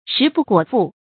食不果腹 注音： ㄕㄧˊ ㄅㄨˋ ㄍㄨㄛˇ ㄈㄨˋ 讀音讀法： 意思解釋： 果：充實。